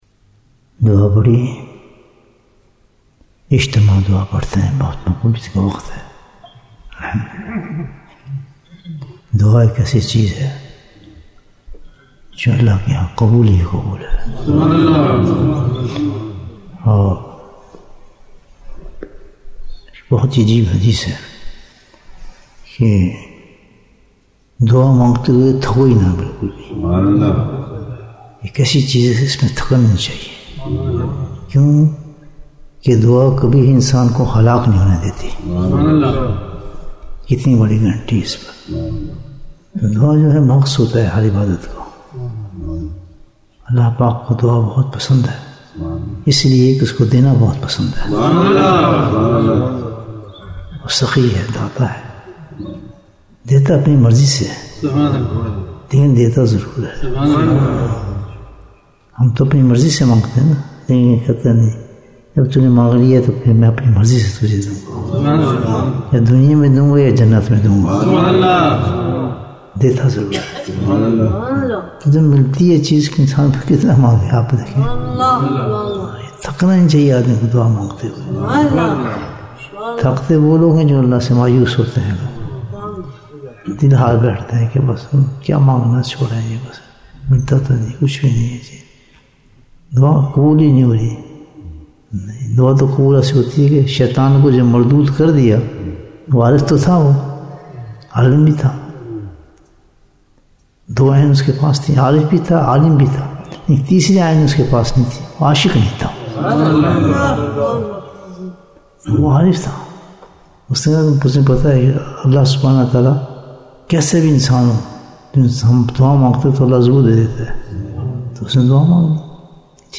Bayan, 7 minutes